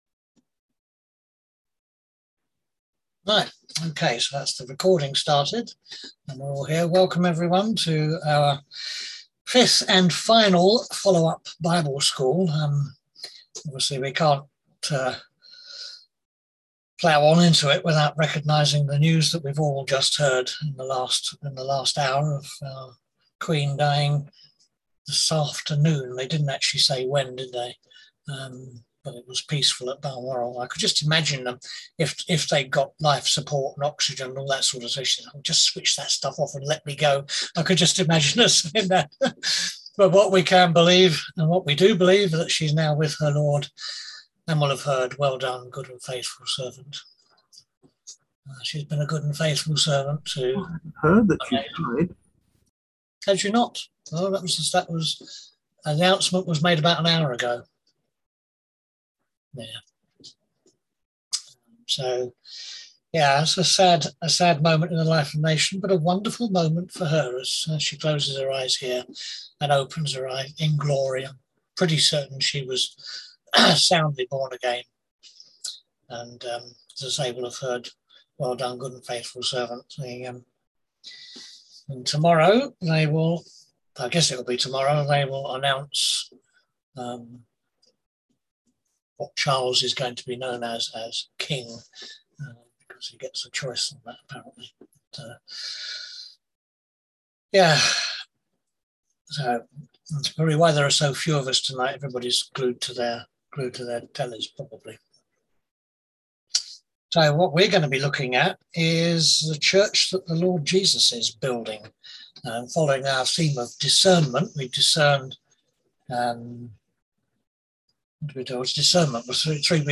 On September 8th at 7pm – 8:30pm on ZOOM